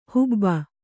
♪ hubba